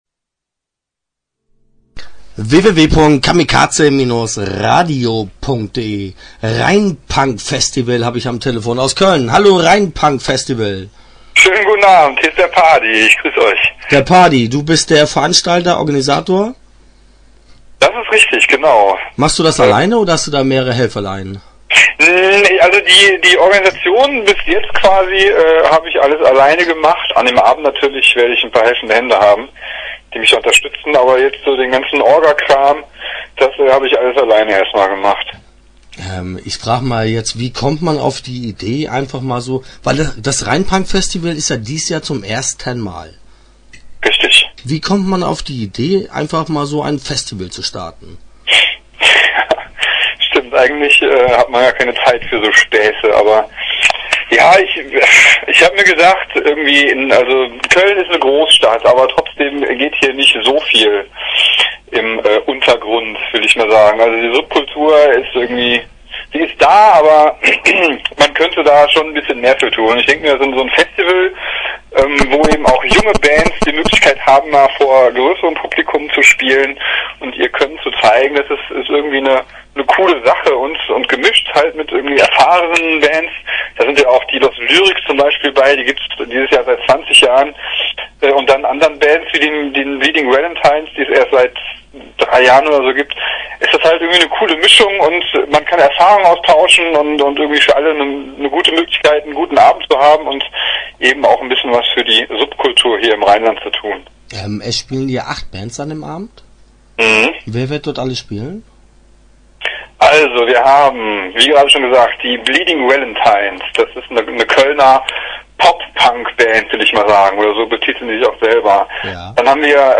Start » Interviews » Rheinpunk-Festival